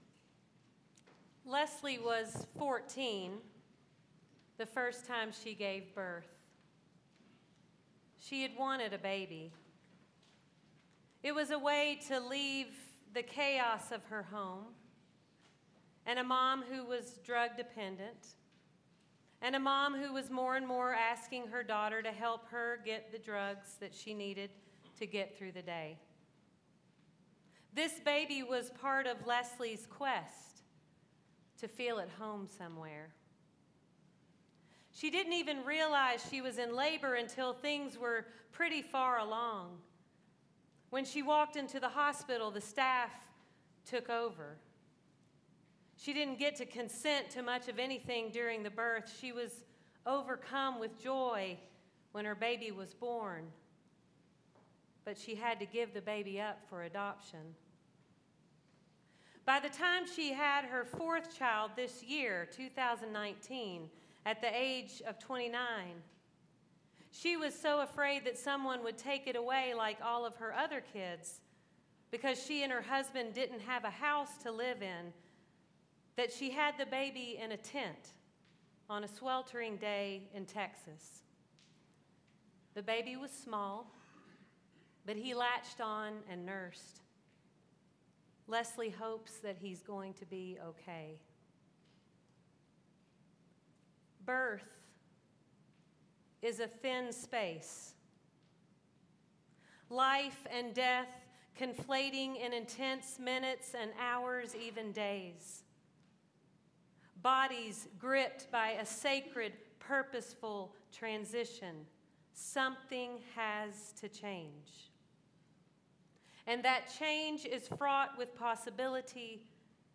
Sermons - Peace-ing Together
Scripture: Isaiah 49: 1-7; John 1: 29-42 Grace Covenant Presbyterian Church, Asheville, NC January 19, 2020